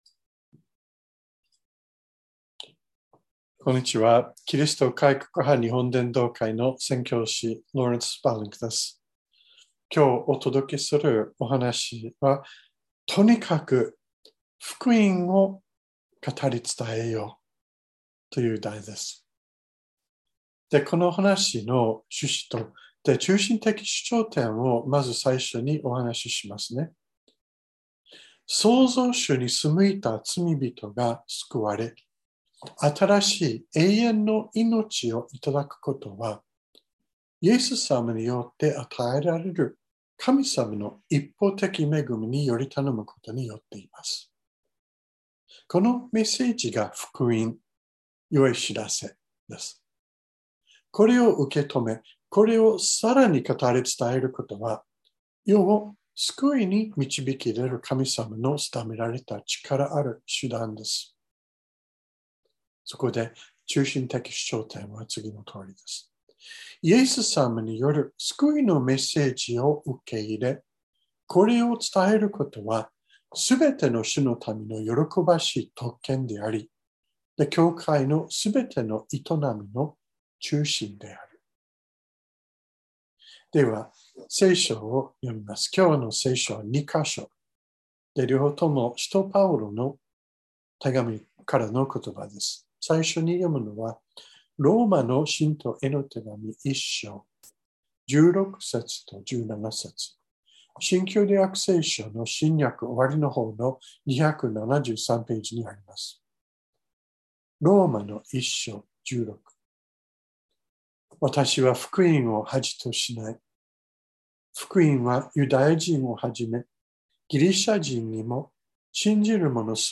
2022年06月26日朝の礼拝「とにかく福音を語り伝えよう」川越教会
説教アーカイブ。